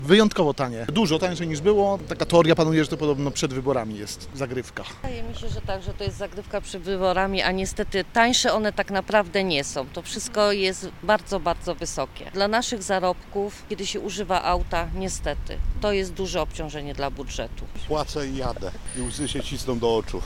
Zapytaliśmy o to mieszkańców Szczecina.
SZCZ-SONDA-CENY-PALIW.mp3